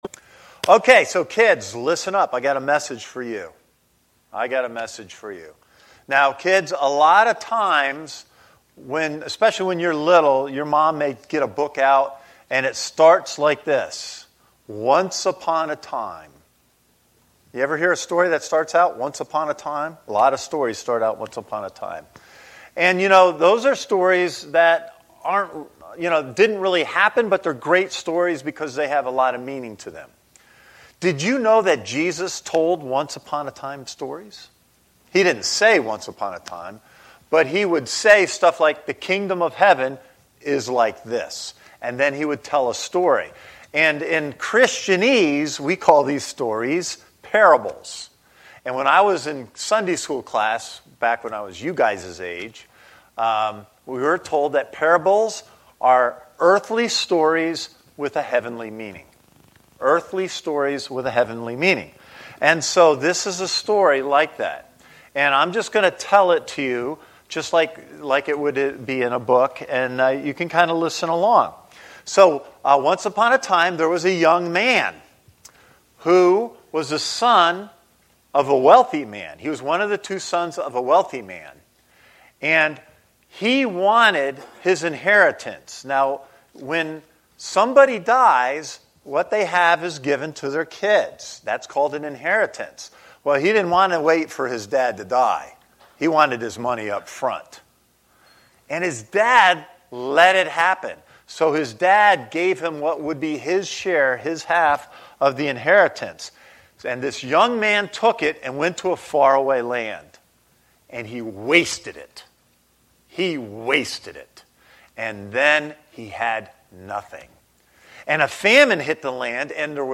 A message from the series "Sunday Service."
This is the main Sunday Service for Christ Connection Church